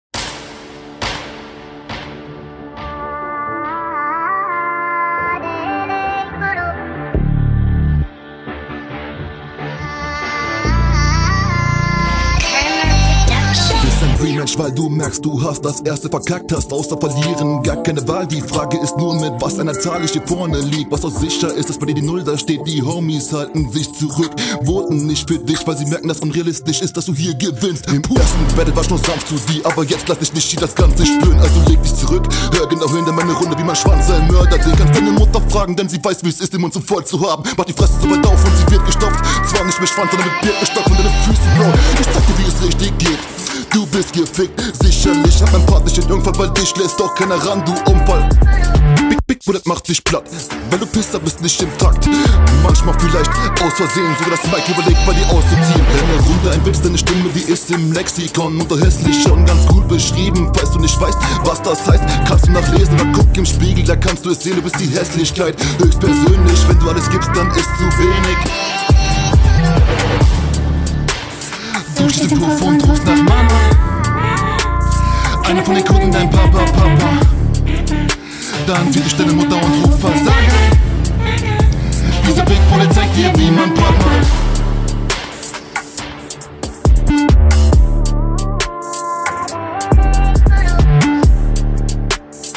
Flowpatterns und Hook sind von der Idee her ganz cool, aber noch nicht routiniert umgesetzt.
Der flow ist gut aber noch etwas ungeübt.